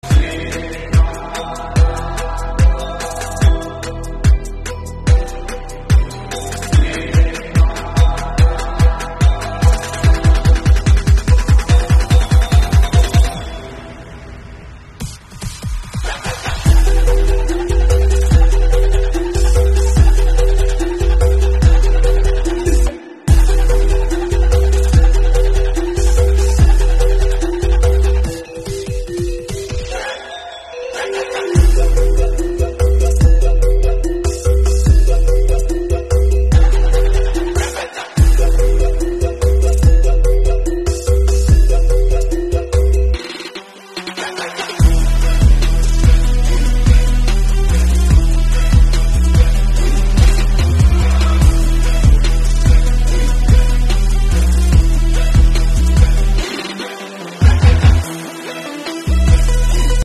VW Safari 1972 Reduksi gear sound effects free download
Mesin alus dan sehat